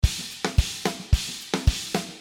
There is one pattern in length of 4 bar in baladi style in 110 bpm.
Malfuf beat is very popular rhythm in the arab style.
15 patterns are tom fills and there are some snare fills all so.